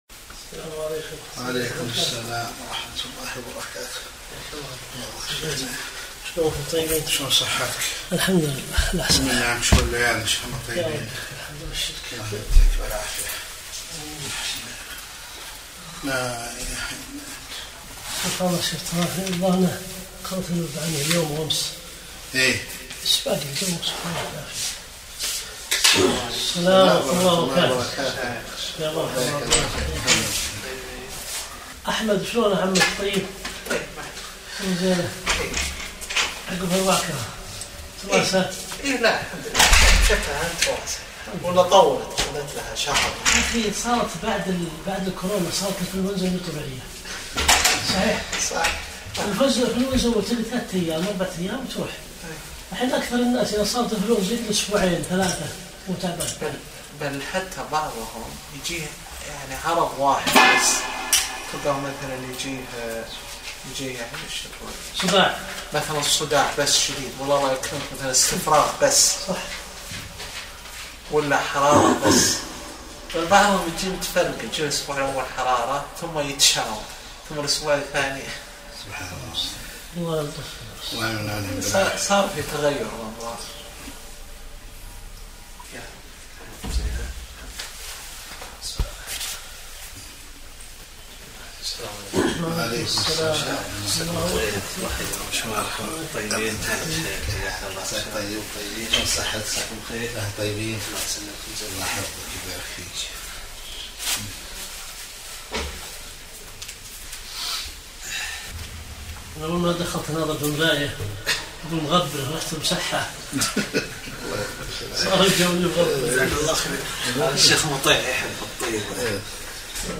درس الأربعاء 64